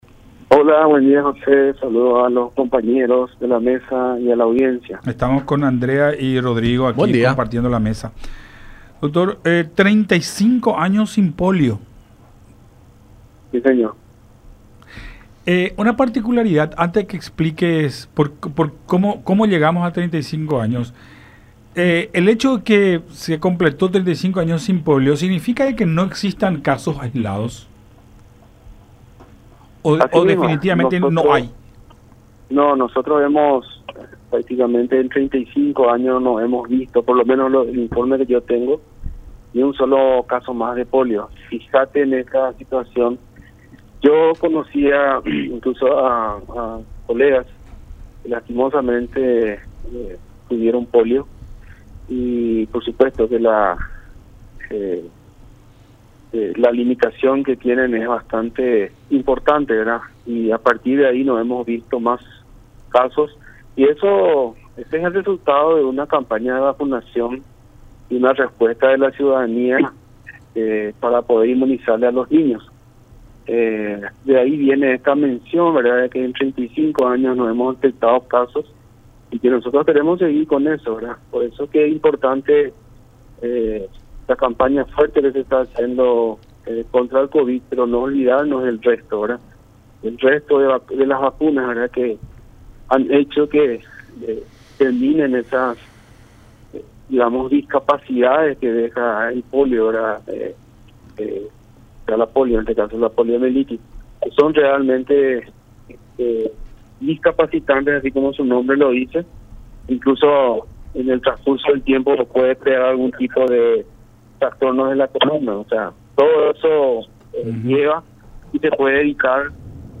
Este es el resultado de la campaña de vacunación y de la respuesta de la ciudadanía”, resaltó Martínez en diálogo con Enfoque 800.